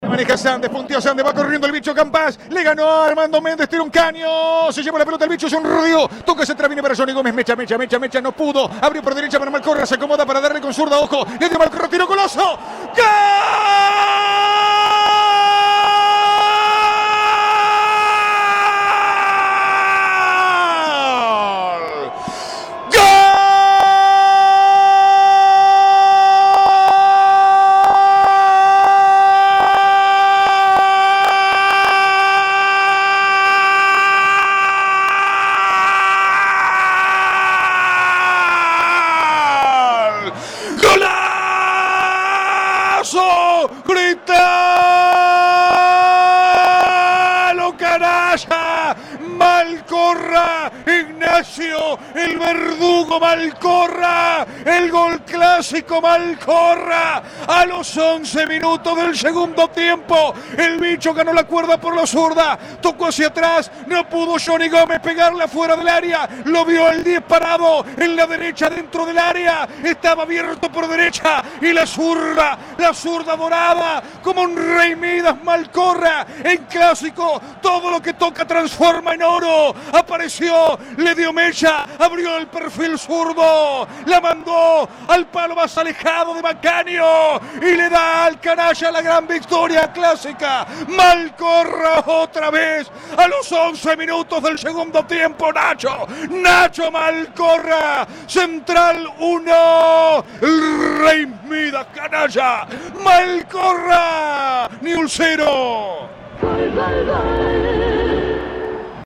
¡Gritalo Canalla! Reviví el relato del gol de Malcorra para ganarle a Newell's